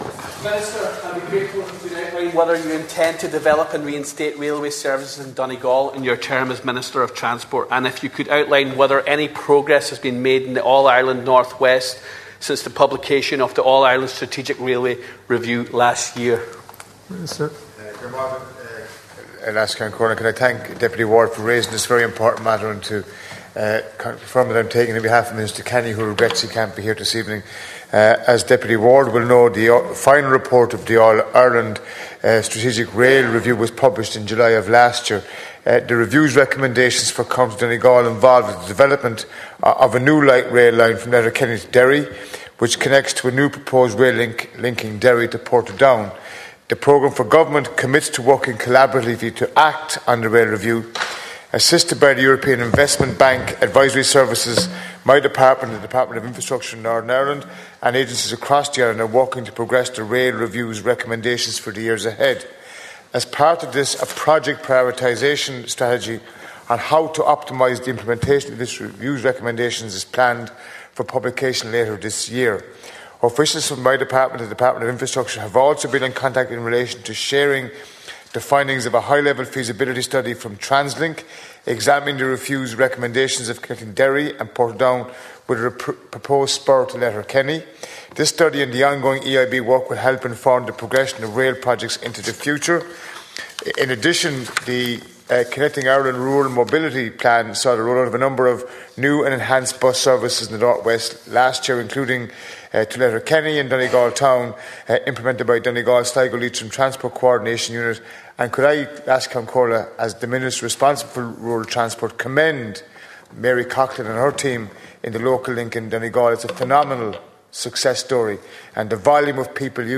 The government’s record on transport infrastructure in Donegal has come under scrutiny in the Dail.